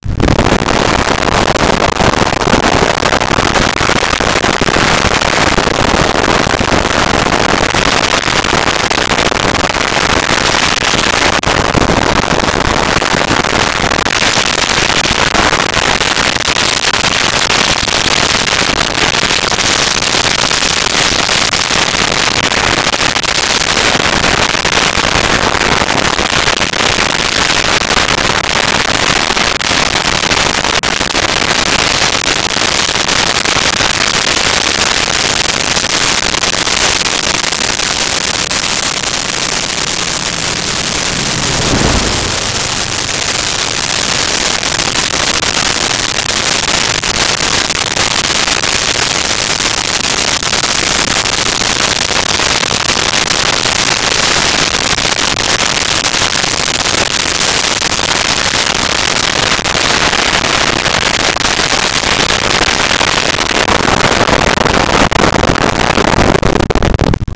Plane Brossard Fast By
Plane Brossard Fast By.wav